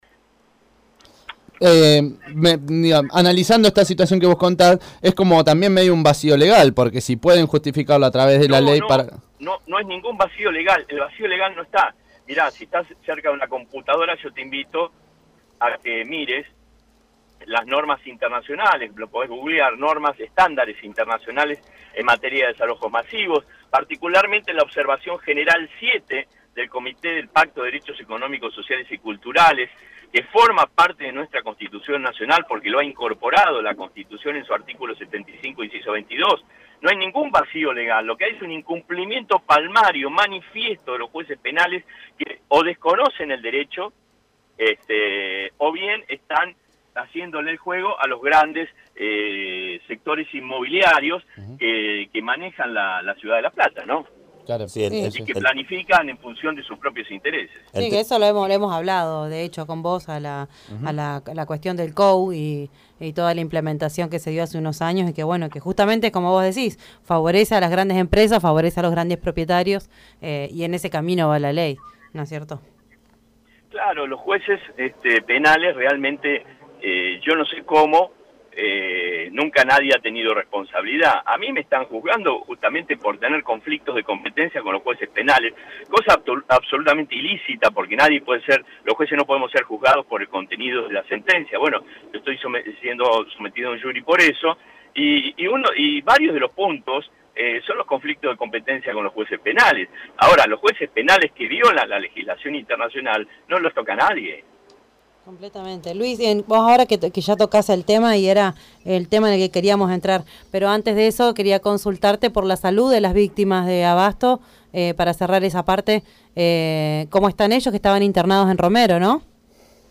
(28-06-18) El juez en lo contencioso administrativo Luis Federico Arias, habló con Los Hijos de la Flor sobre la situación habitacional en Abasto y el jury de enjuiciamiento que pesa en su contra, que va a comenzar el próximo 2 de agosto.